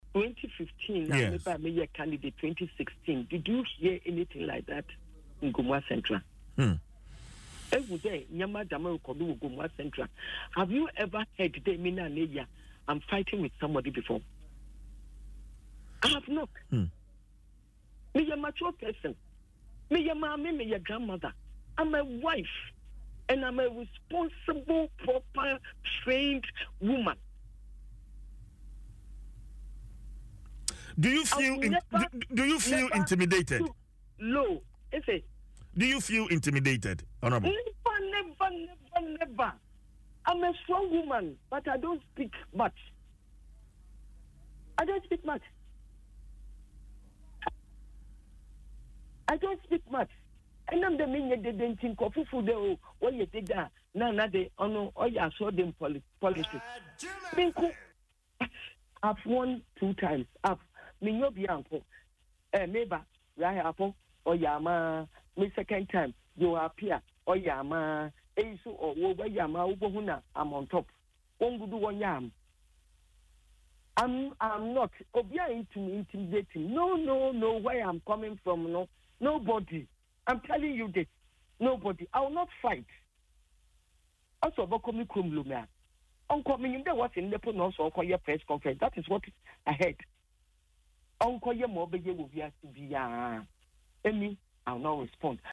In an interview on Adom FM’s Dwaso Nsem, the MP, without mincing words, stated that she is a mature and responsible woman who will not stoop low to reply to insults.